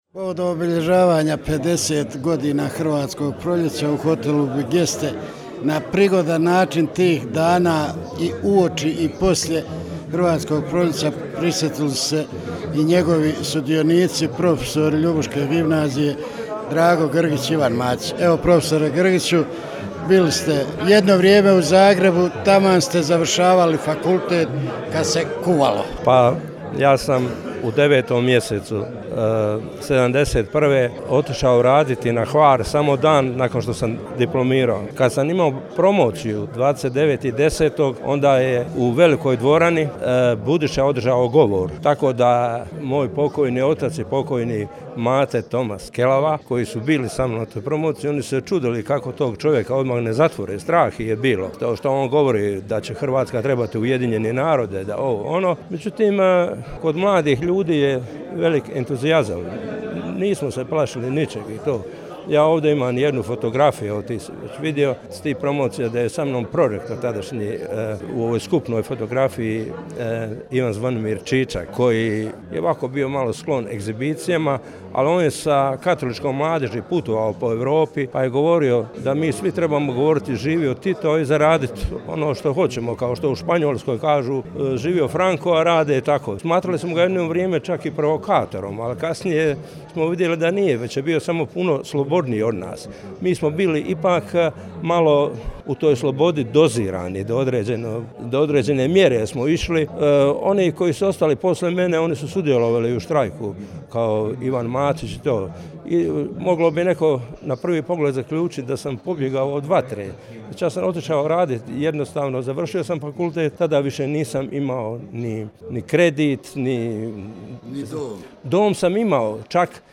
U hotelu Bigeste u Ljubuškom sinoć je Udruga Hercegovačka kulturna baština Bilig organizirala prigodni program obilježavanja 50 godina Hrvatskog proljeća.